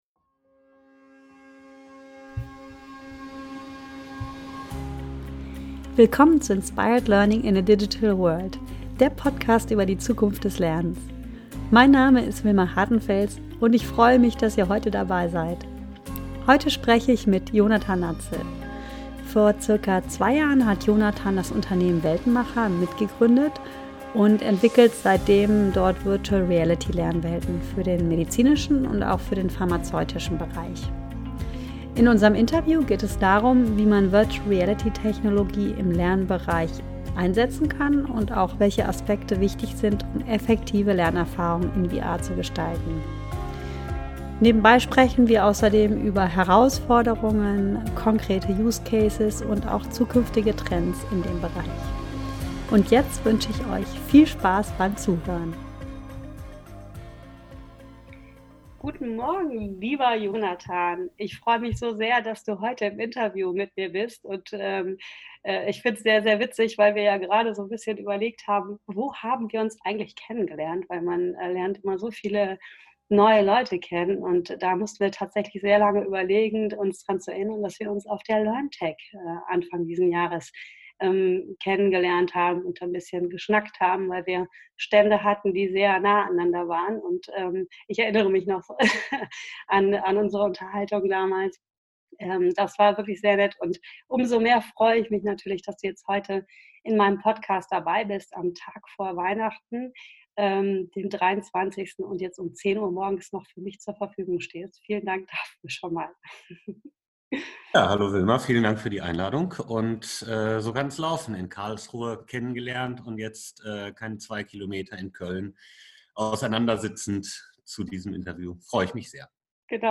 Im Interview gibt er Einblicke in die Voraussetzungen für effektive Lernszenarien in der virtuellen Welt.